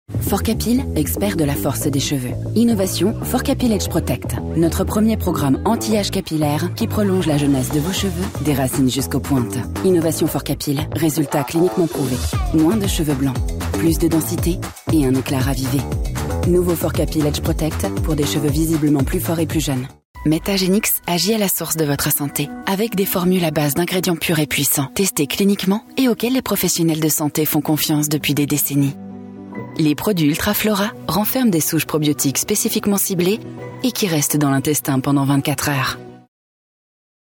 Conversational
Friendly